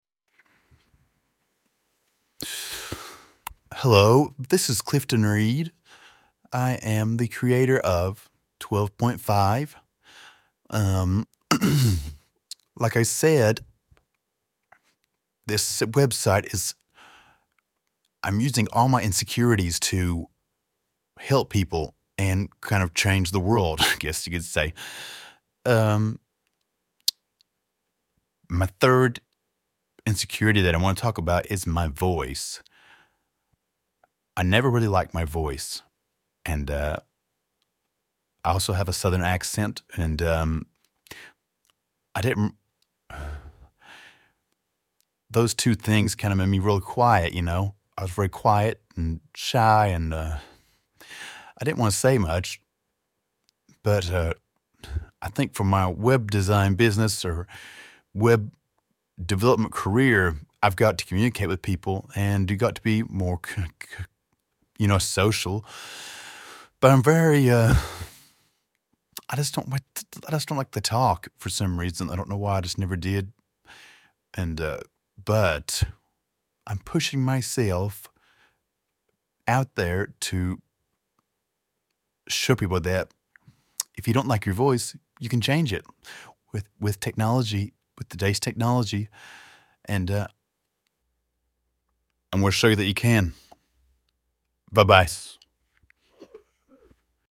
I used a service by Eleven Labs.